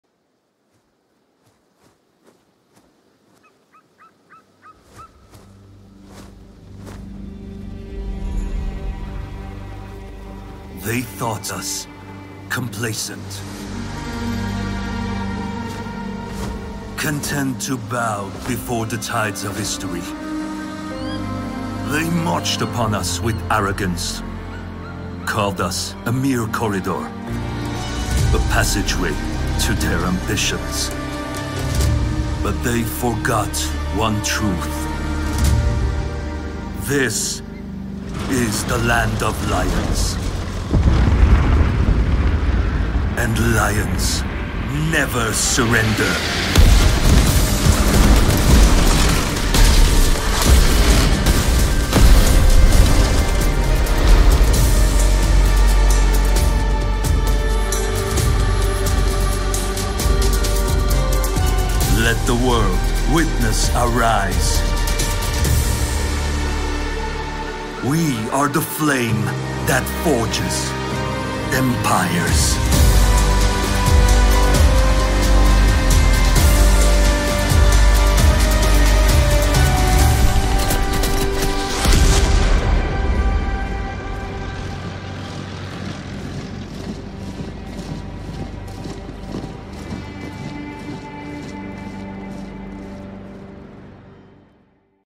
Foreign Language Voice Samples
Commercial Demo
Mic: Rode NT2A
Audio Interface: Arturia AudioFuse
BaritoneBassDeepLow
WarmKindFriendlyElegantSeriousCuriousElderlyAdultEnergeticConversationalNeutral